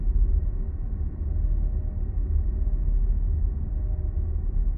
shell_hum.ogg